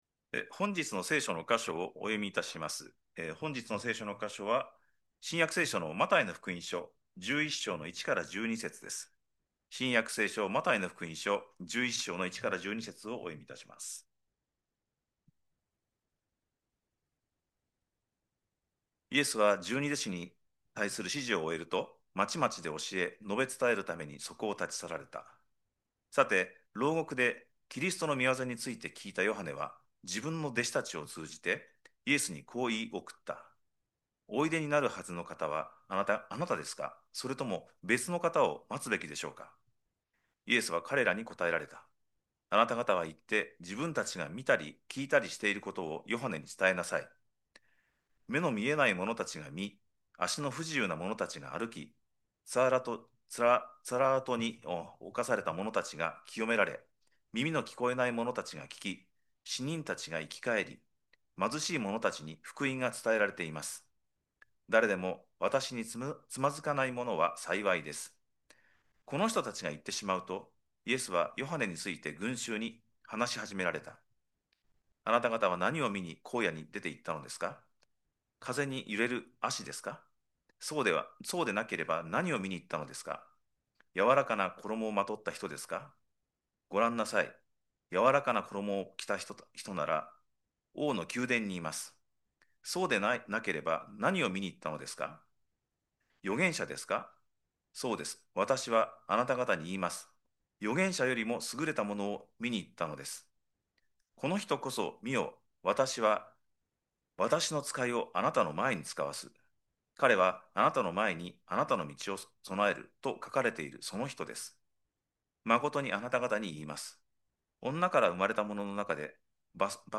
2025年12月14日礼拝 説教 「クリスマスの期待」 – 海浜幕張めぐみ教会 – Kaihin Makuhari Grace Church